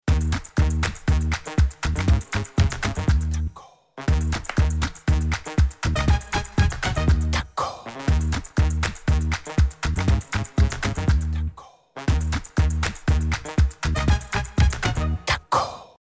Jingle / Erkennungsmelodie
👉 kurz, prägnant, sofort im Kopf